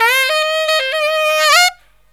63SAXMD 01-R.wav